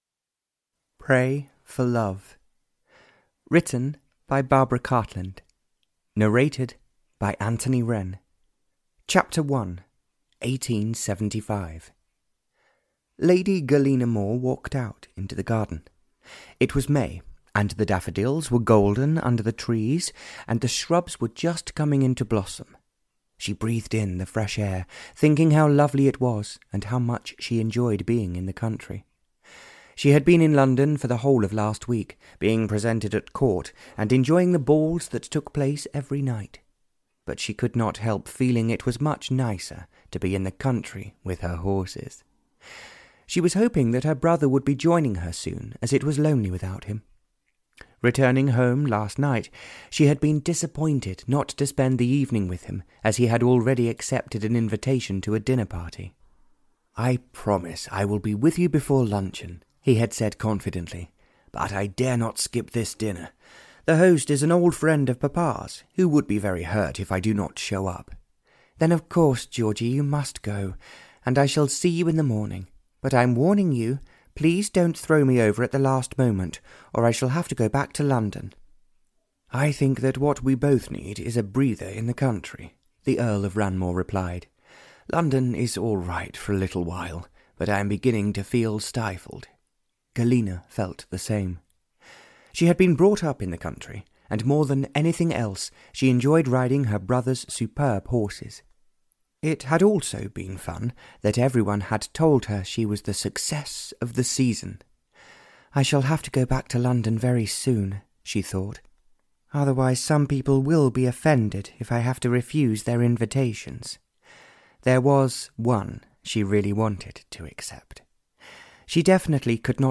Audio knihaPray For Love (Barbara Cartland’s Pink Collection 67) (EN)
Ukázka z knihy